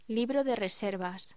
Locución: Libro de reservas